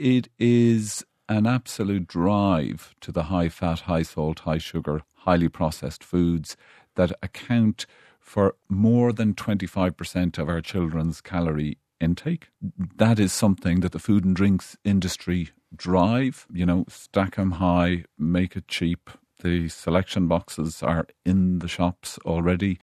Consultant Endocrinologist